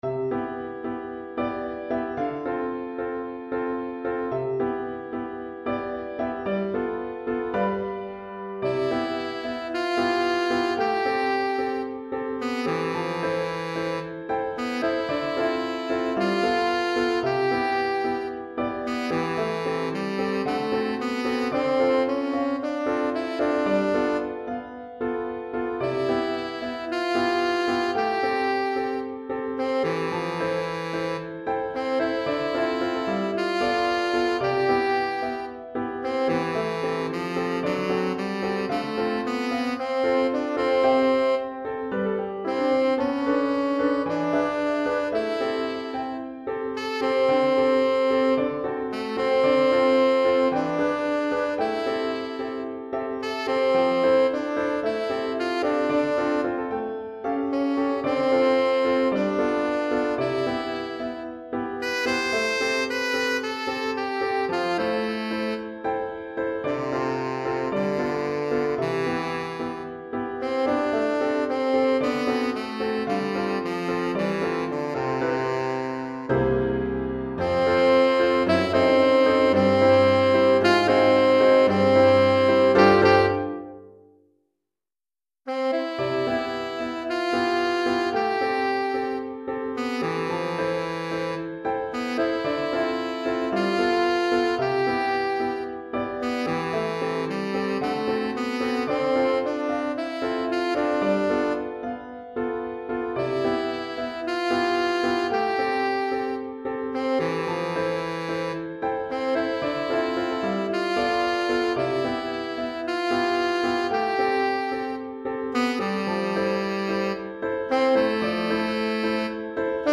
Saxophone Soprano ou Saxophone Ténor et Piano